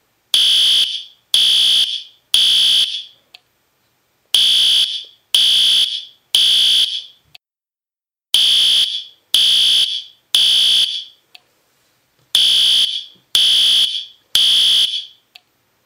Evacuate Area Buzzer .mp3 {repeating = bang-bang-bang-pause}
Evacuate_Area_Buzzer.mp3